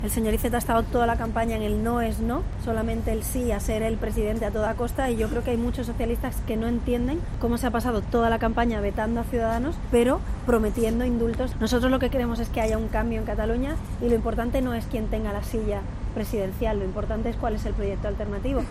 En declaraciones en la carpa que Ciudadanos ha instalado en la plaza Universidad de Barcelona, Arrimadas ha redoblado sus críticas hacia el candidato socialista en el último día de la campaña electoral de las catalanas del 21 de diciembre.